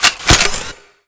sci-fi_weapon_reload_03.wav